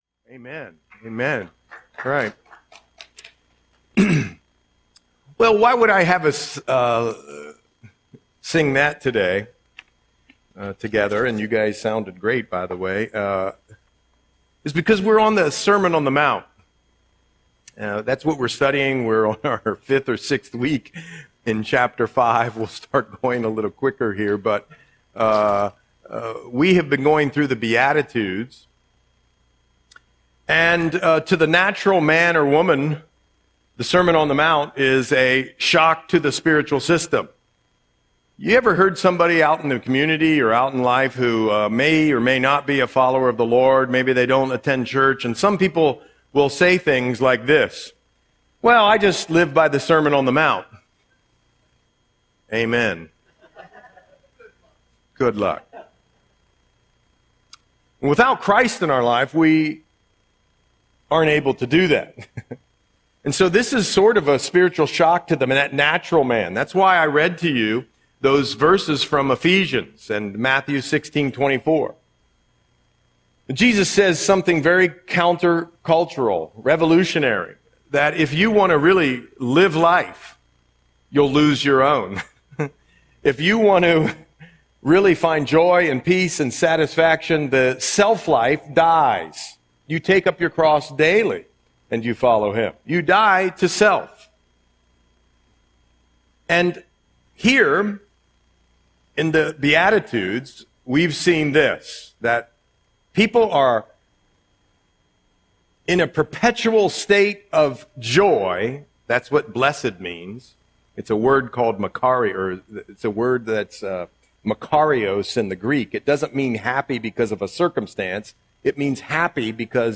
Audio Sermon - December 14, 2025